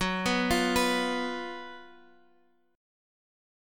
GbM7sus4 chord